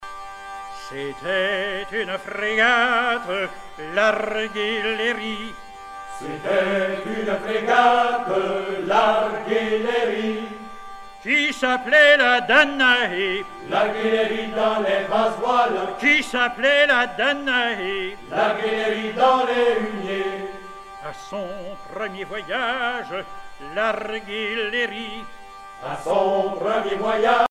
chanteur(s), chant, chanson, chansonnette
Genre laisse
Catégorie Pièce musicale éditée